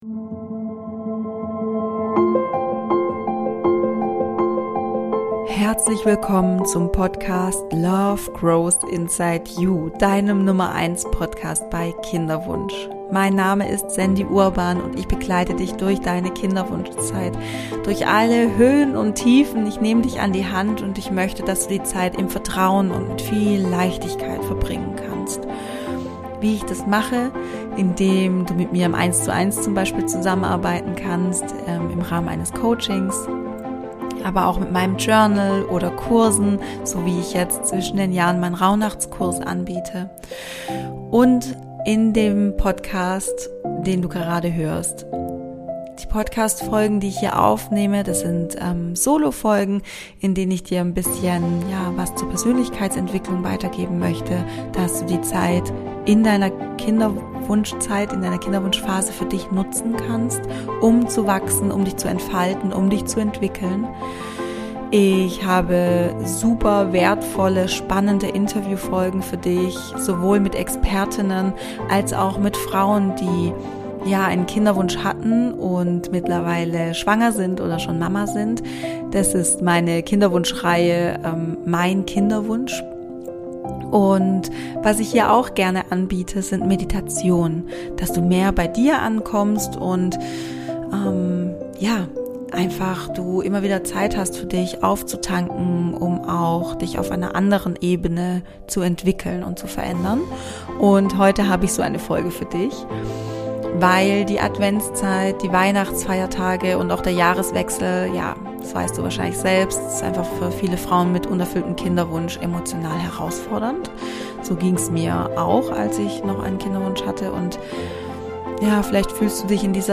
Meditation: Adventszeit, Weihnachten & Silvester mit Kinderwunsch ~ Love Grows Inside You - Dein Podcast bei Kinderwunsch Podcast
Du Liebe, in dieser Folge lade ich dich zu einer besonderen Meditation ein – ein Moment der Ruhe, nur für dich.